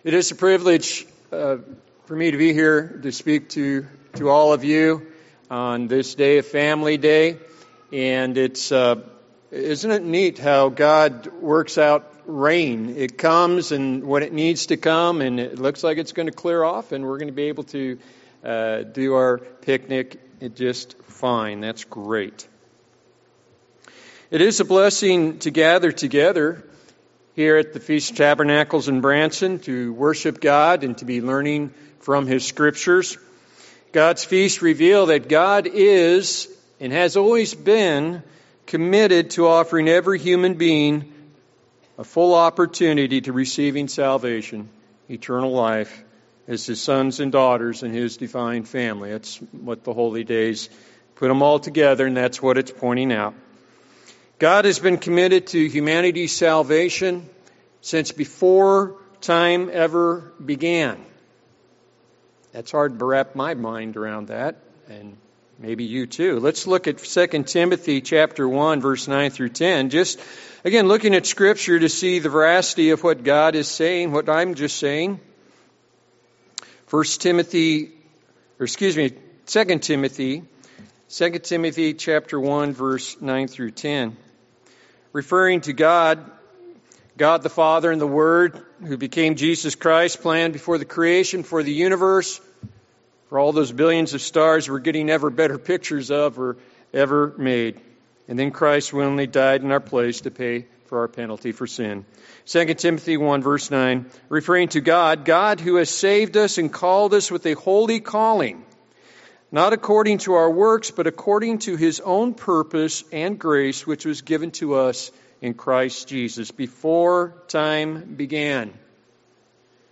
This sermon was given at the Branson, Missouri 2022 Feast site.